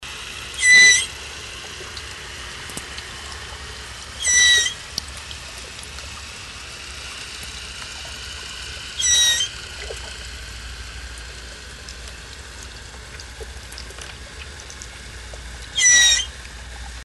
Florida Manatee
Voice
Manatees communicate by using calls that have been described as squeaks, chirps or grunts. The way the call sounds depends on the situation and the reason the manatee is communicating.
manatee-call.mp3